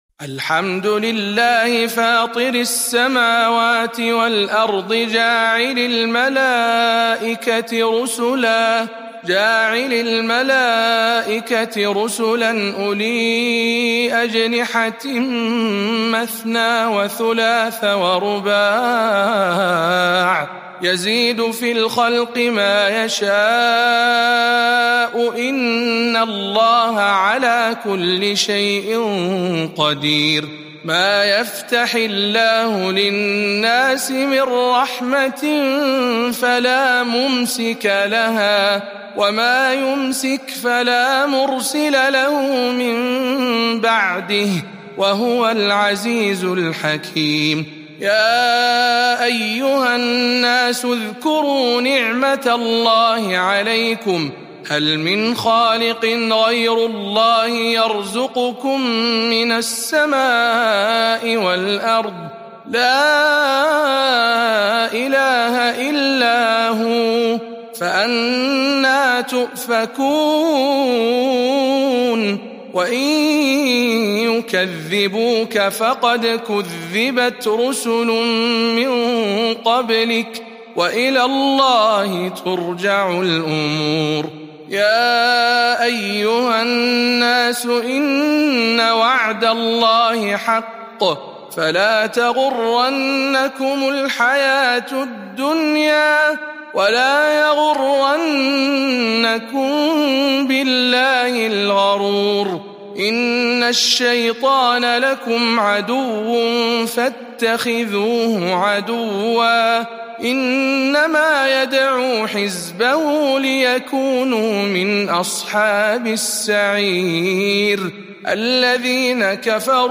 034. سورة فاطر برواية شعبة عن عاصم